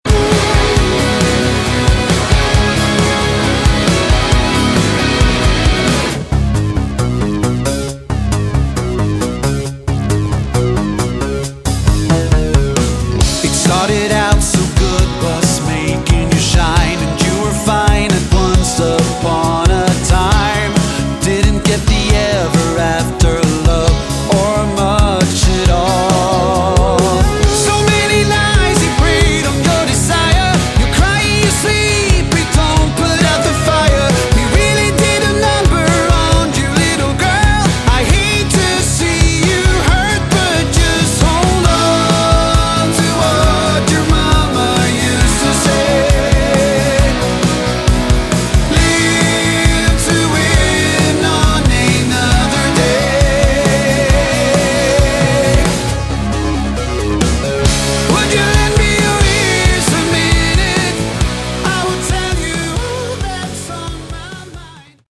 Category: AOR
Vocals
Guitars
Bass
Keyboards
Drums